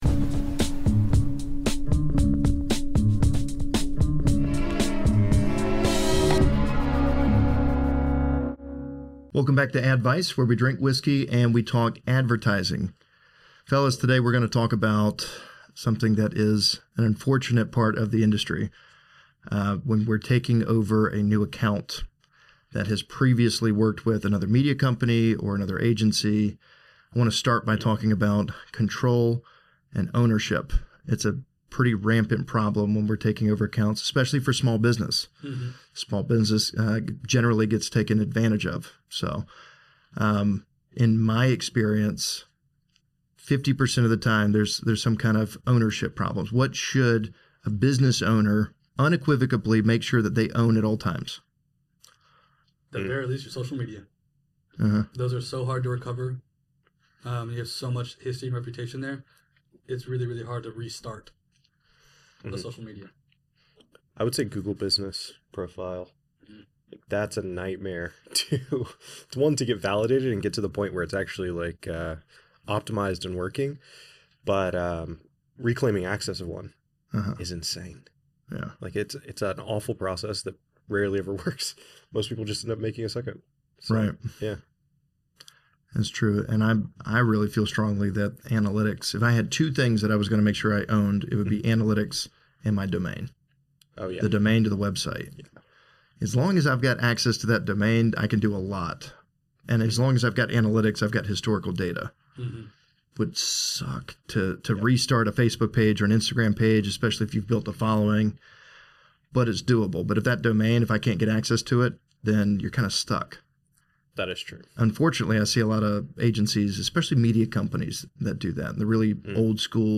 AdVice is filmed in Witcraft Podcast Studio and sponsored by WiT Group, a performance-based digital marketing agency.